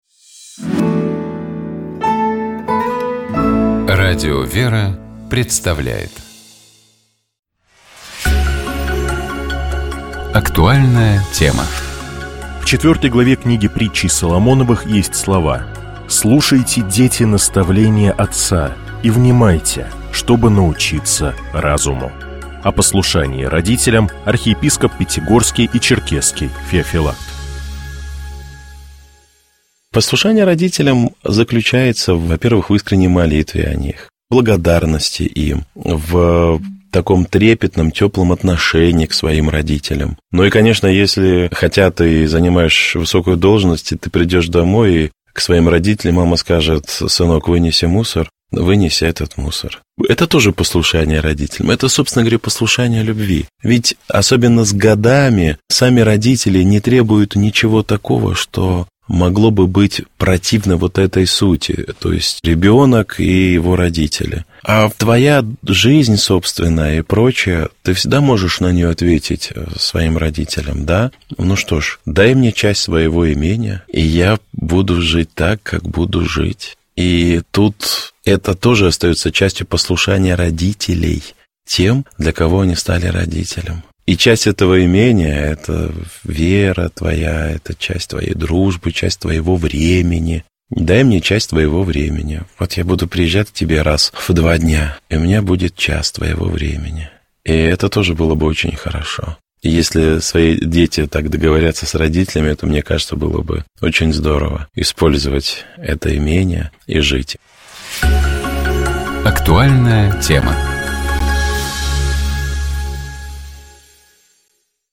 В беседе она рассказала о своём творческом пути, воспитании детей через искусство и важности преемственности традиций.